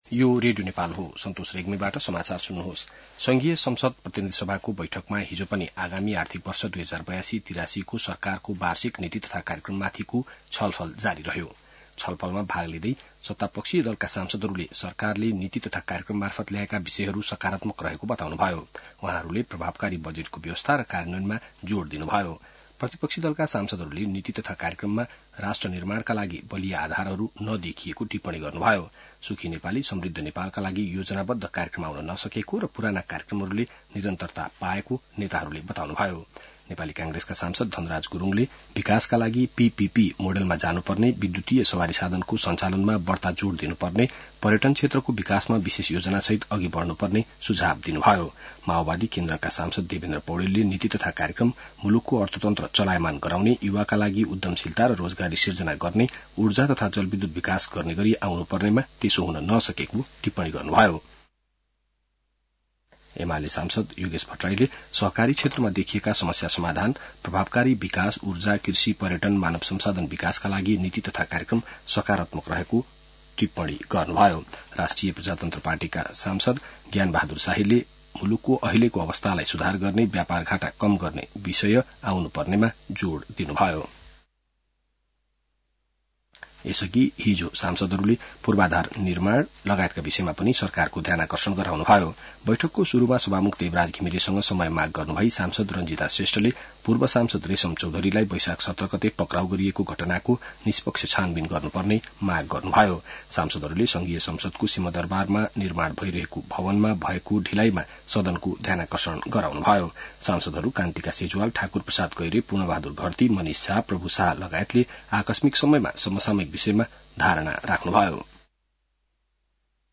बिहान ६ बजेको नेपाली समाचार : २४ वैशाख , २०८२